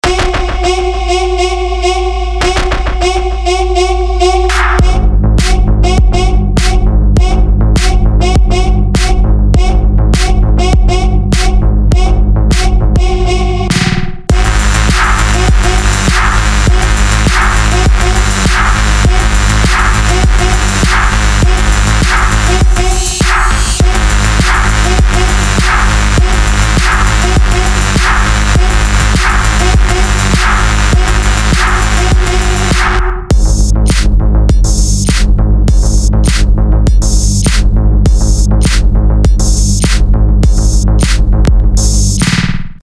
Рингтоны без слов
Рингтоны техно
Midtechno , Мощные басы , Midtempo